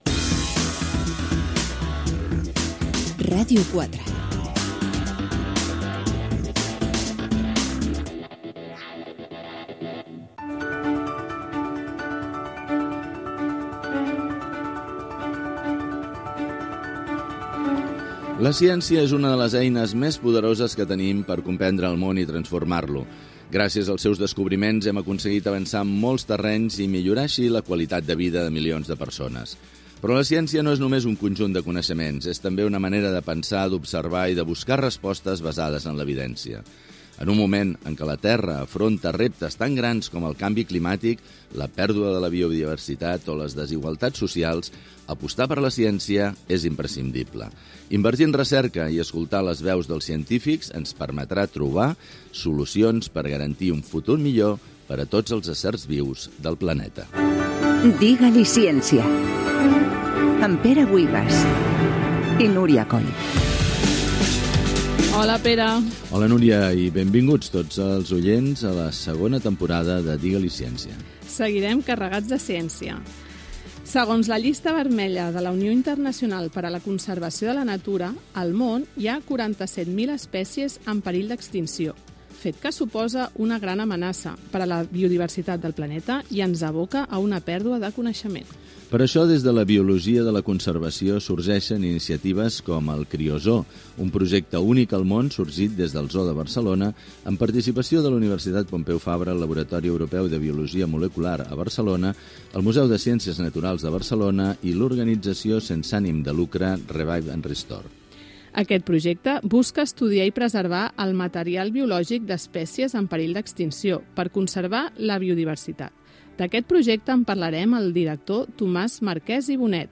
Indicatiu de la ràdio, presentació del primer programa de la segona temporada d'emissió (2025-2026). La importància de la recerca científica, les espècies en extinció.
Divulgació